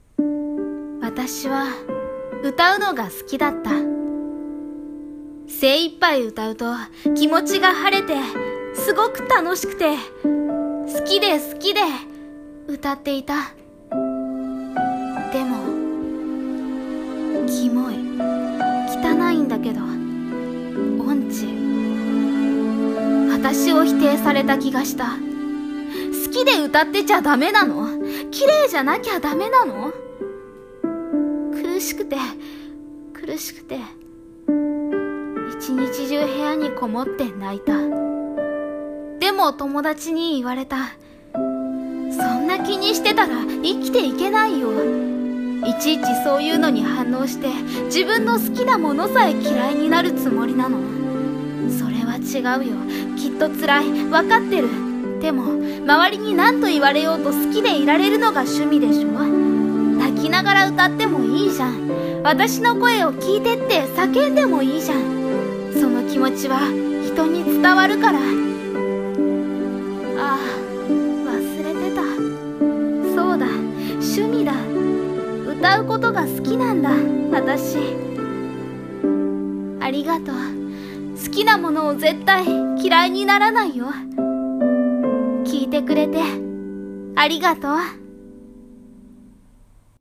【声劇】私の声を聞いて。